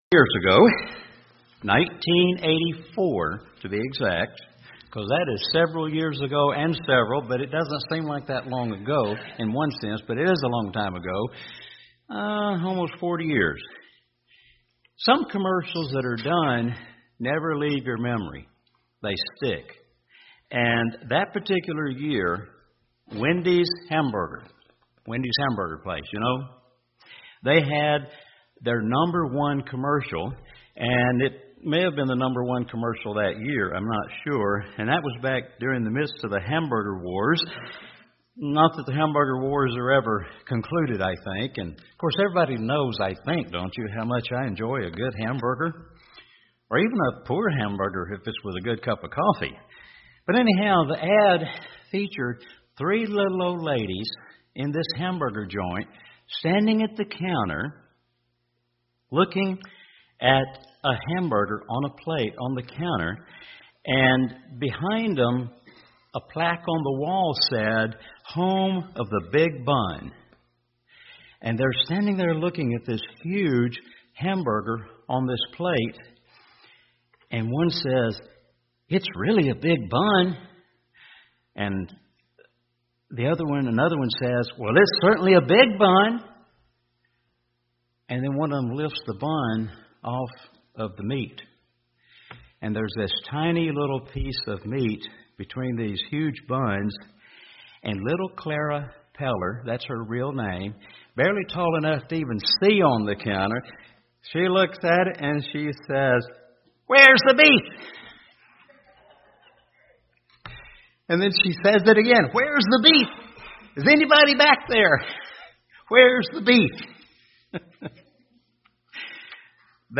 Now in this message we are getting to the meat of the matter on this last Day of Unleavened Bread.